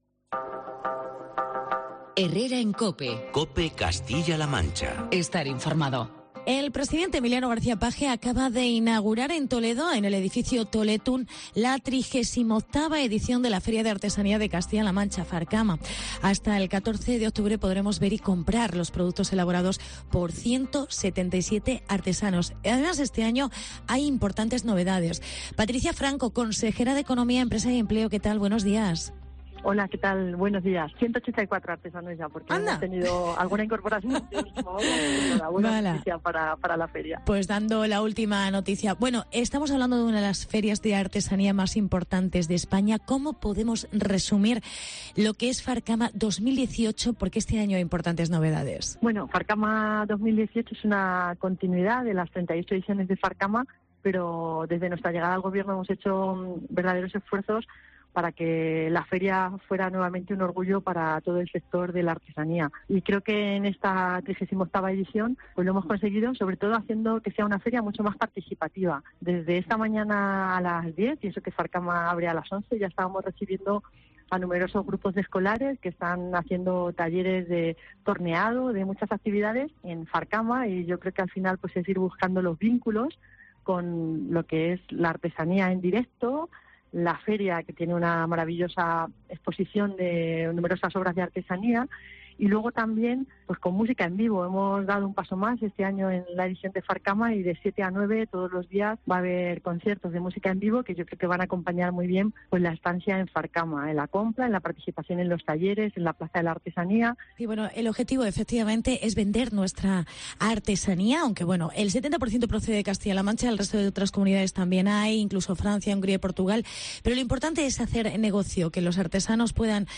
Inauguración de XXXVIII FARCAMA. Entrevista con la consejera: Patricia Franco